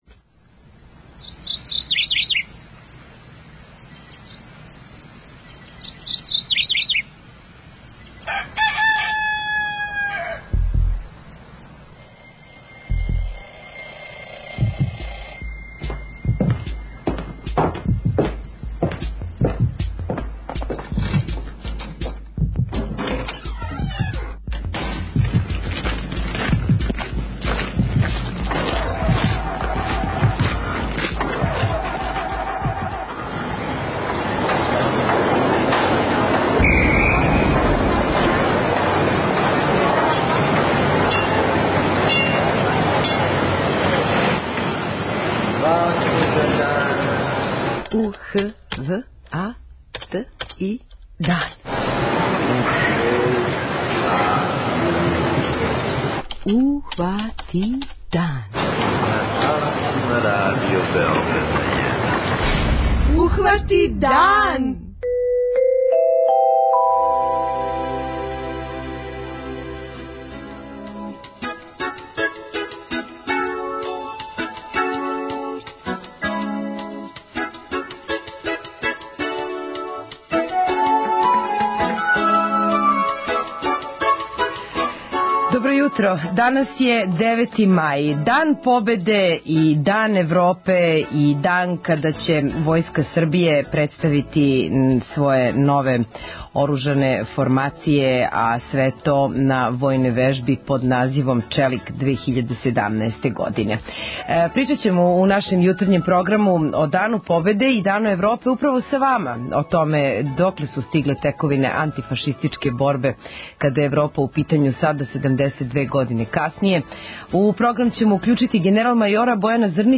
О току уписа деце у вртиће у Београду разговарамо са вршиоцем дужности секретара за образовање и дечију заштиту Славком Гаком.
Разговарамо са станарима новобеоградских блокова који ће протестима показати шта мисле о изградњи нових стамбених зграда у њиховом окружењу.